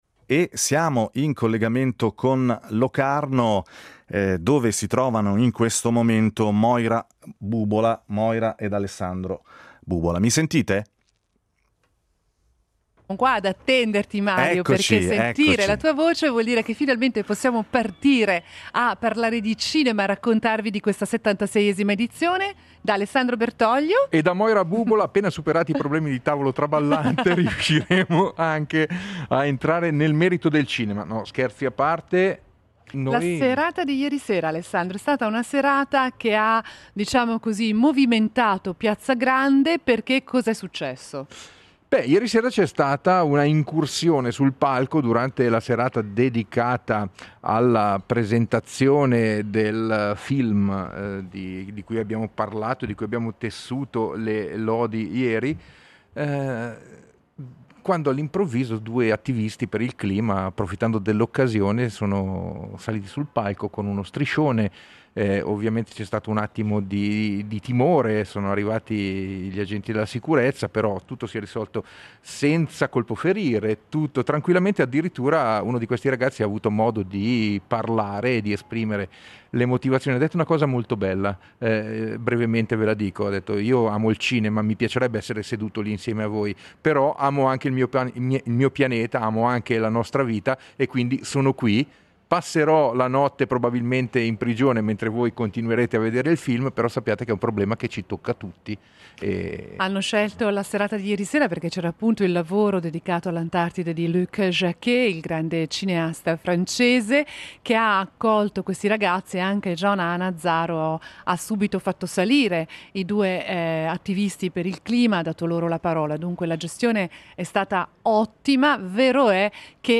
Interviste, anticipazioni, notizie, recensioni e retroscena dall’appuntamento col grande cinema più atteso dell’anno.